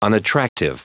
Prononciation du mot unattractive en anglais (fichier audio)
Prononciation du mot : unattractive
unattractive.wav